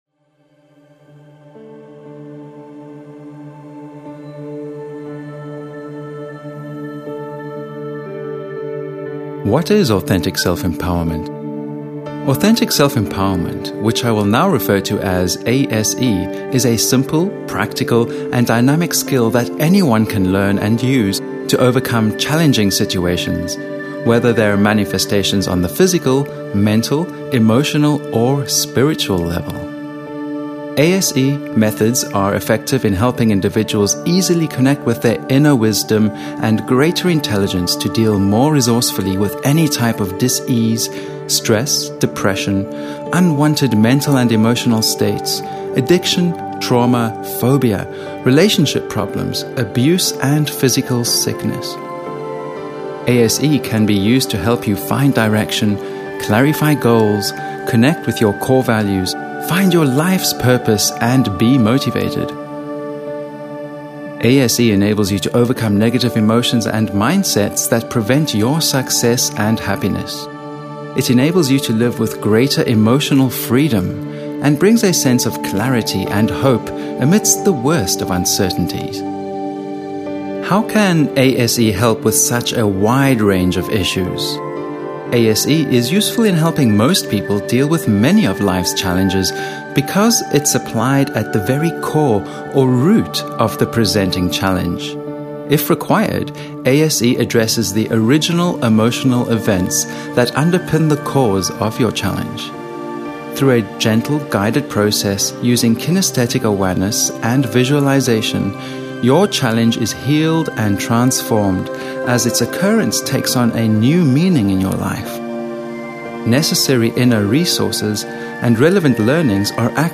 This unique recording includes inductive voice techniques as well as specialized music and audio technologies which contribute to its effectiveness.